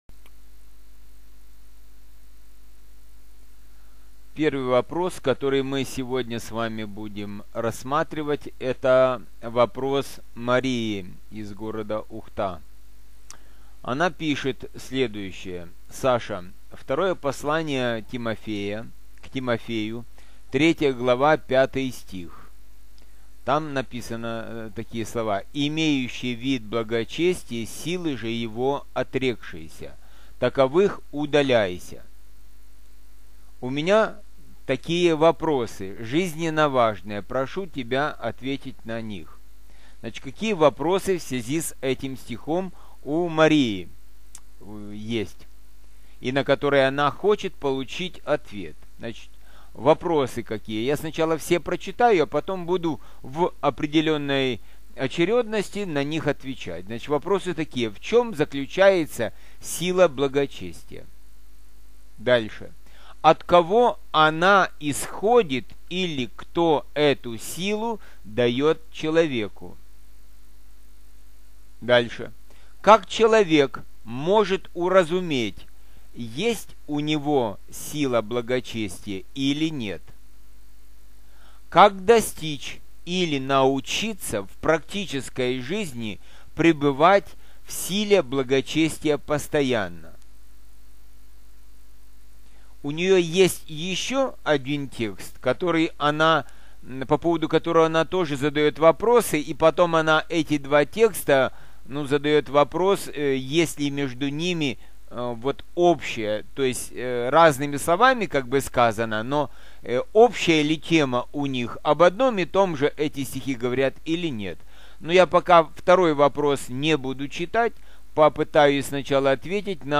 16.10.15 г. Вопросы и ответы